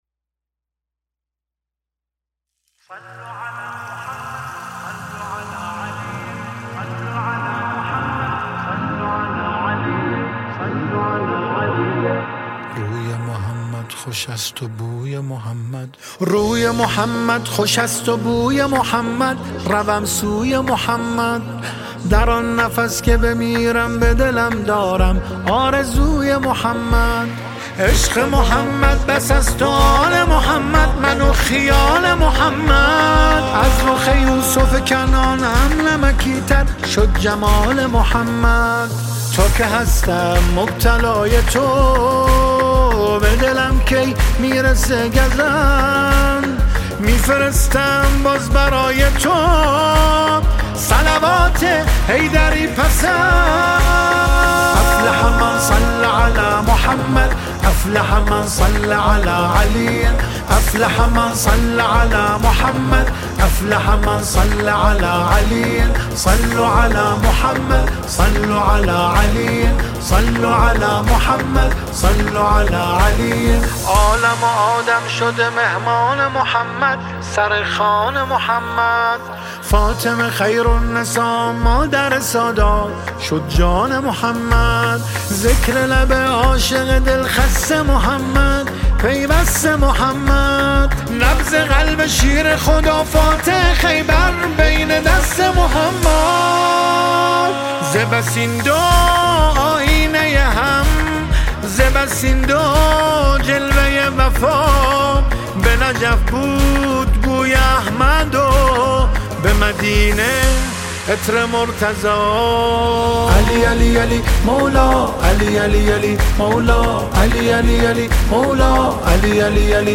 با نوای دلنشین
مولودی